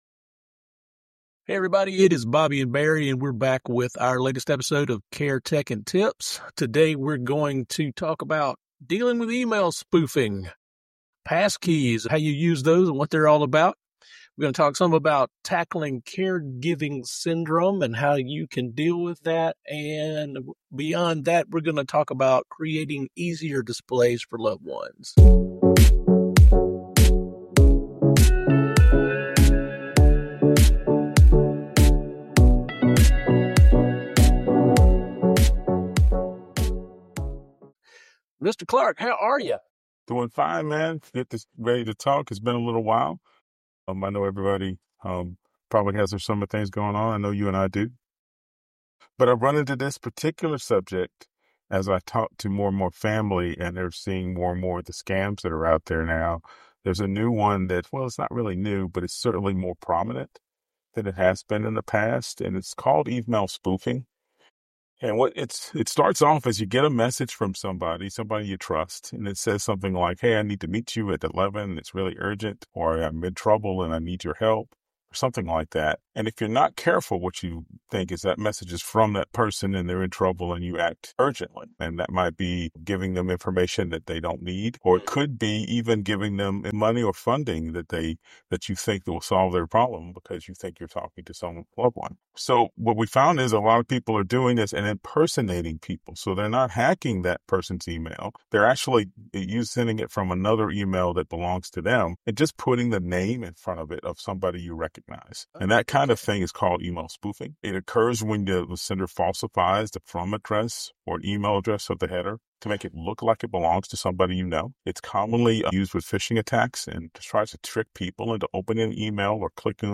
"Care Tech & Tips" is like tech support meets family therapy, with two guys who’ve been there.